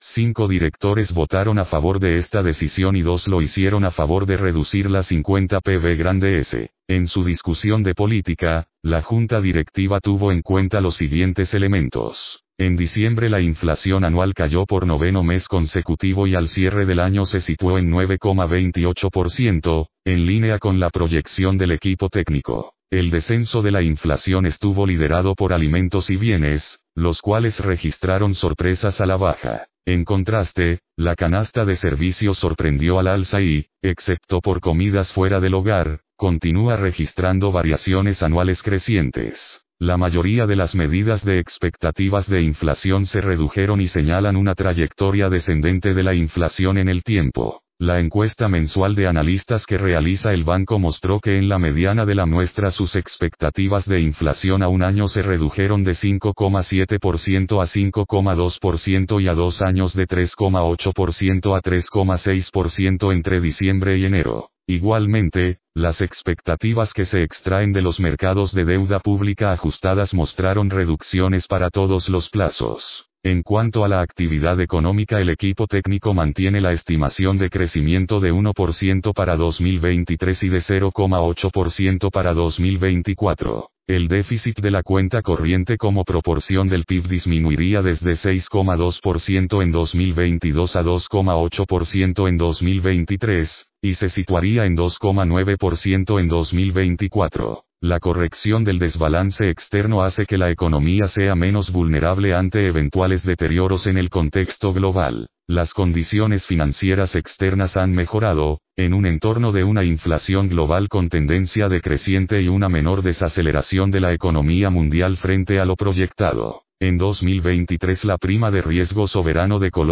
La rueda de prensa fue el pasado 31 de enero.